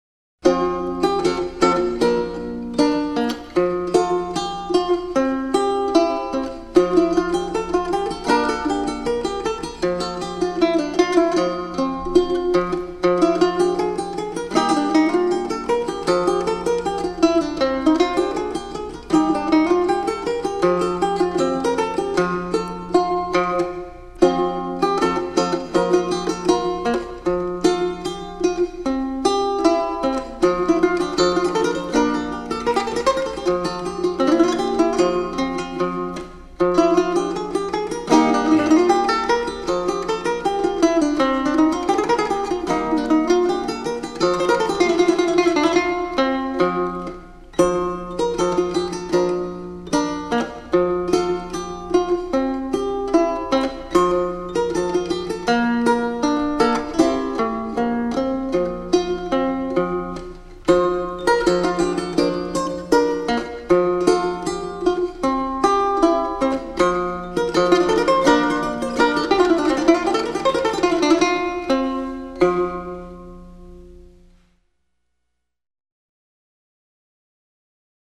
replica Renaissance and Baroque guitars